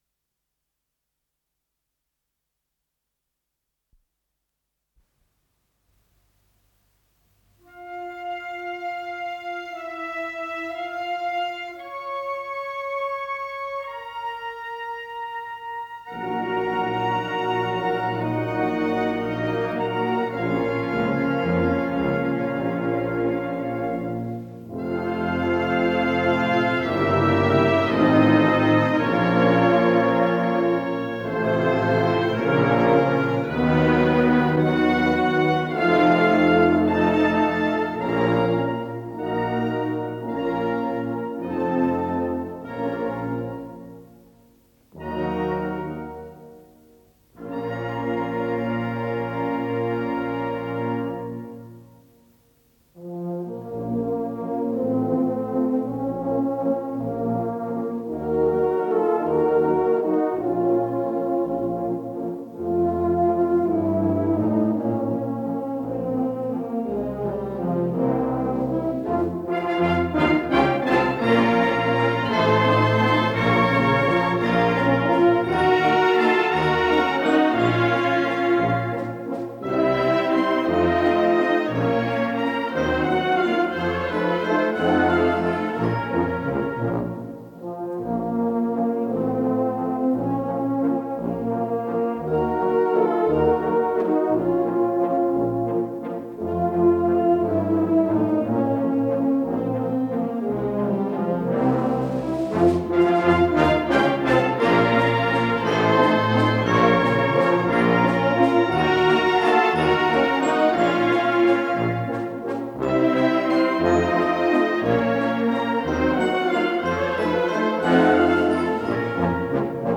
с профессиональной магнитной ленты
ПодзаголовокВальс
ВариантДубль моно